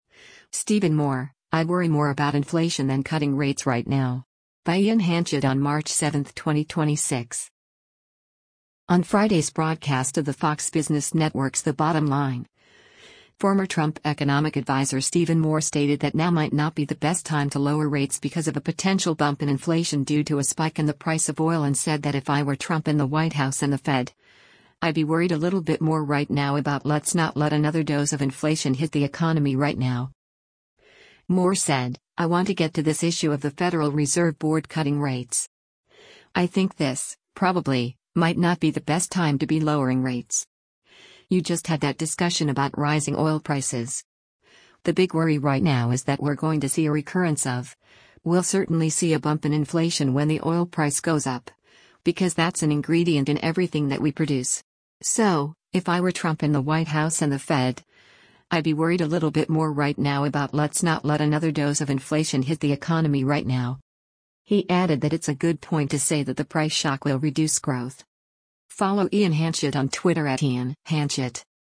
On Friday’s broadcast of the Fox Business Network’s “The Bottom Line,” former Trump Economic Adviser Stephen Moore stated that now might not be the best time to lower rates because of a potential bump in inflation due to a spike in the price of oil and said that “if I were Trump and the White House and the Fed, I’d be worried a little bit more right now about let’s not let another dose of inflation hit the economy right now.”